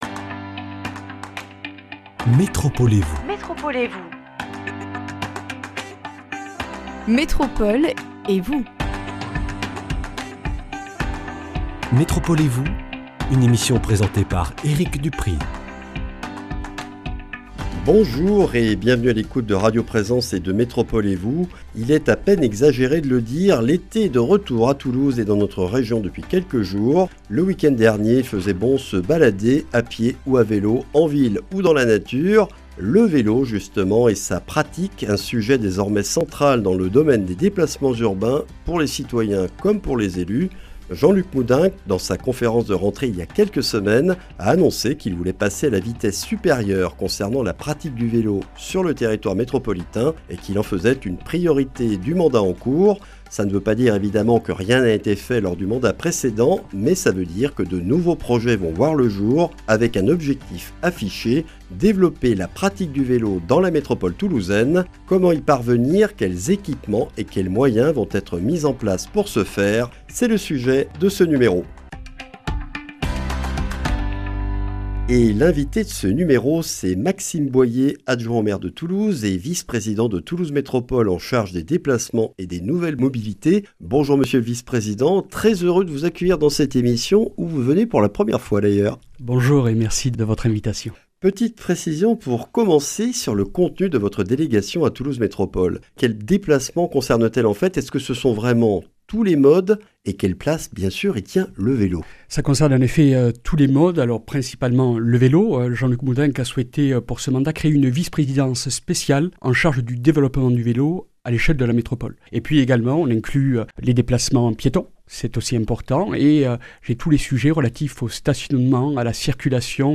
Quels sont les projets (Plan vélo), équipements (pistes cyclables) et moyens (vélôToulouse électriques, prime vélo) mis en place pour atteindre cet objectif ? Des réponses et des précisions avec Maxime Boyer, adjoint au maire de Toulouse, vice-président de Toulouse Métropole chargé des déplacements et des nouvelles mobilités.